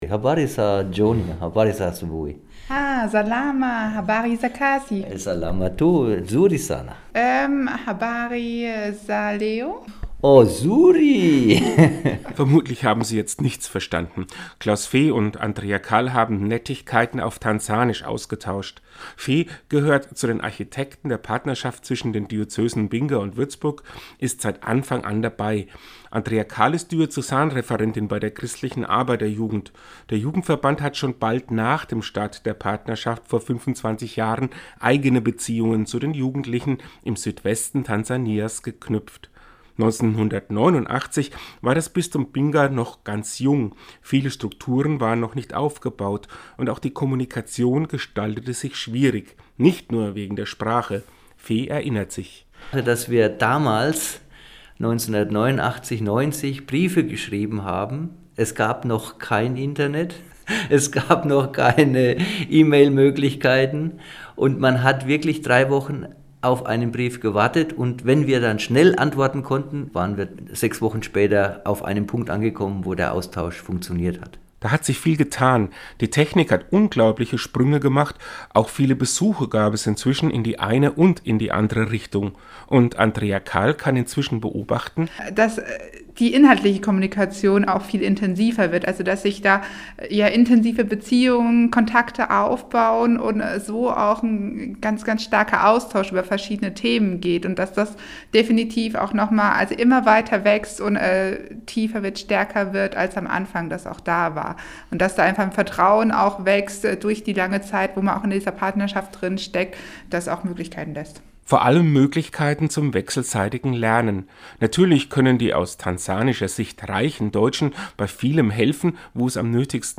Seinen Radiobeitrag können sie unten anhören.